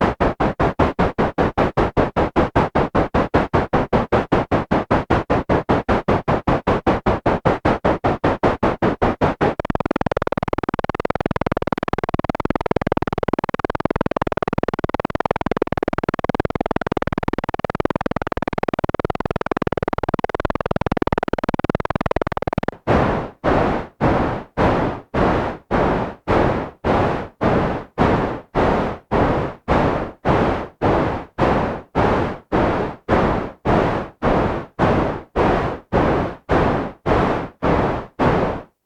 D     ENVELOPE SHAPER
Source : Noise through Filter into Envelope Shaper. Matrix pins : 7 H + 10 D
Setting of pots : Attack = 1, On = 0.6, Decay = 5.8
In this example it's set at about position 2 to get a steady repeat.
The sound starts in the 'short' position, then changes into 'very short' and then the 'long' version.